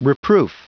Prononciation du mot reproof en anglais (fichier audio)
Prononciation du mot : reproof